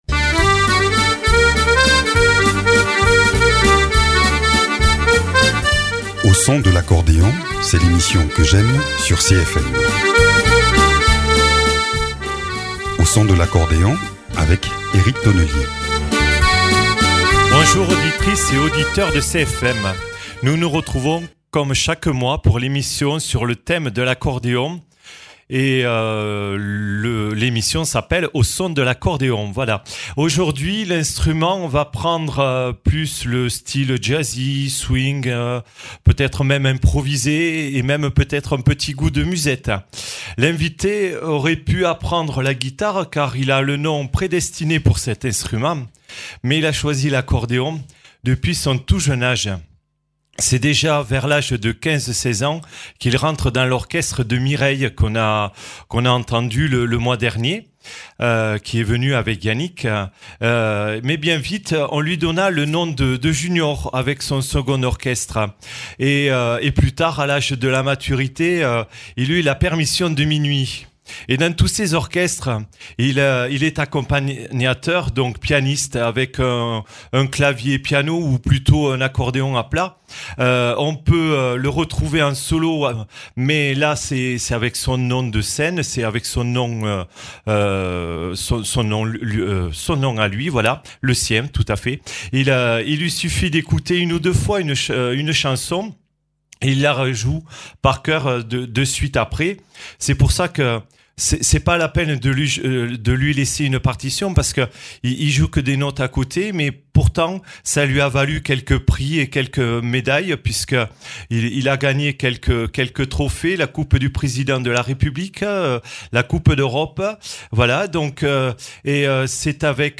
musicien et professeur de musique.